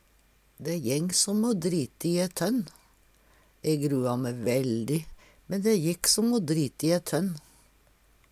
dæ jeng som å drite i ett hønn - Numedalsmål (en-US)